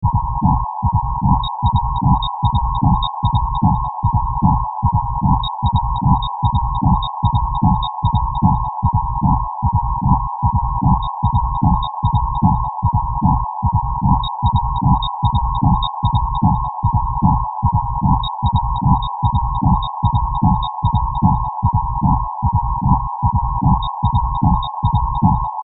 area di azione musicale elettronica popolare sperimentale